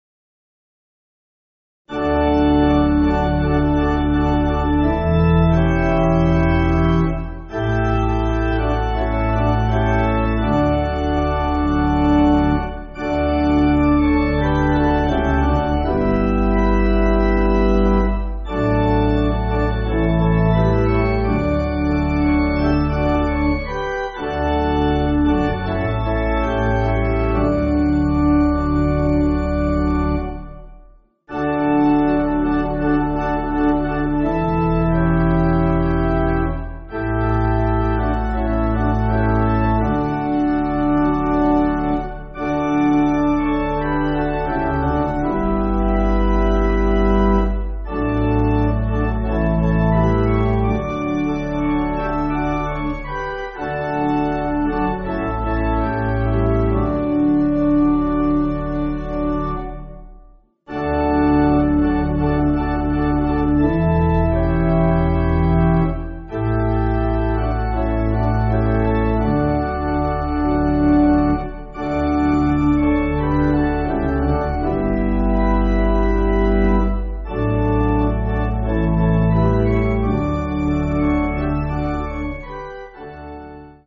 Malagasy melody
Organ